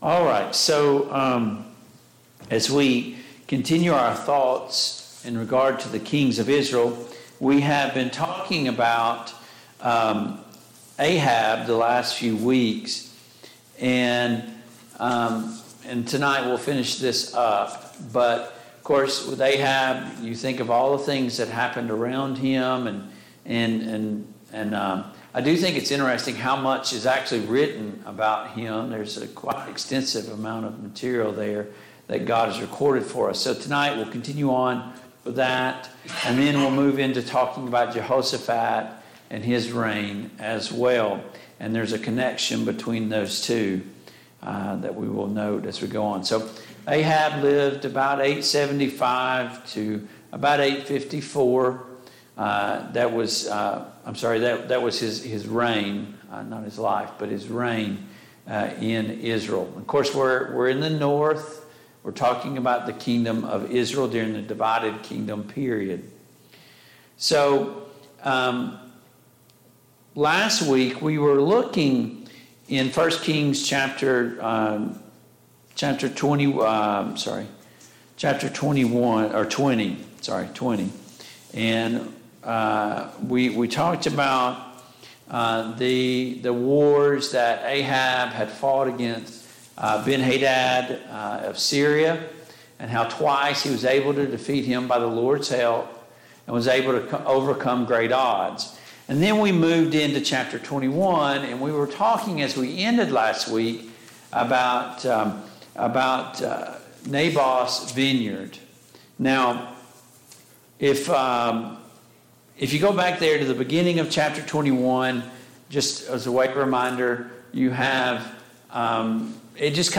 Mid-Week Bible Study